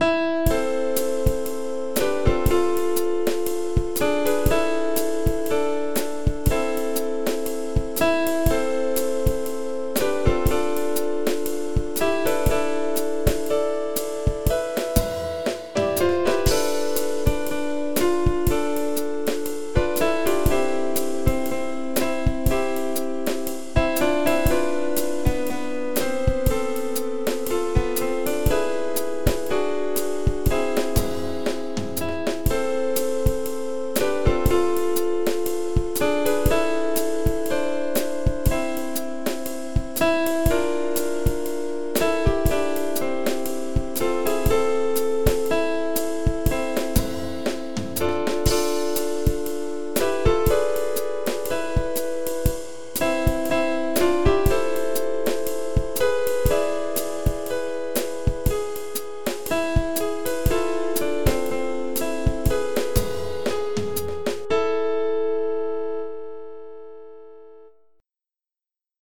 acompañamiento